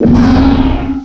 sovereignx/sound/direct_sound_samples/cries/beartic.aif at master